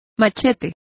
Complete with pronunciation of the translation of machetes.